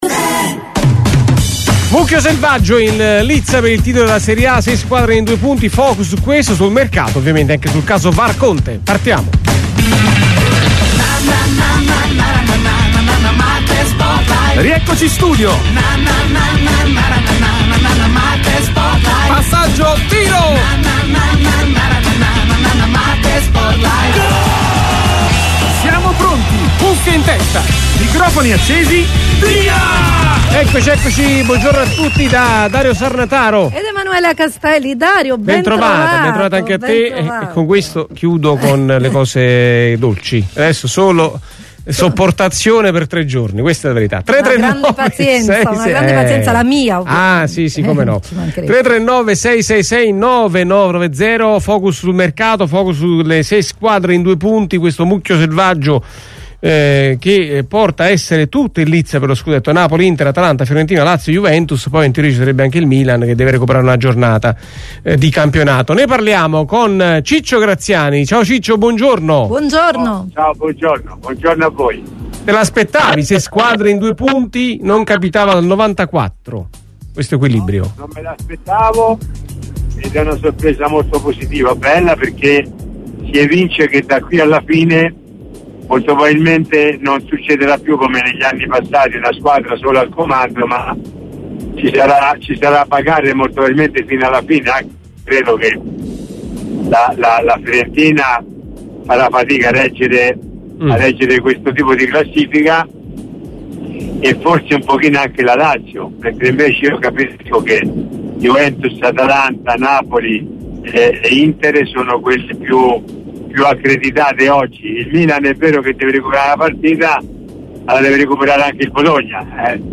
MARTE SPORT LIVE è UNA TRASMISSIONE SPORTIVA, UN TALK CON OSPITI PRESTIGIOSI, OPINIONISTI COMPETENTI, EX TECNICI E GIOCATORI DI VALORE, GIORNALISTI IN CARRIERA E PROTAGONISTI DEL CALCIO ITALIANO E INTERNAZIONALE.